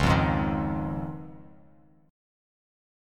C#add9 chord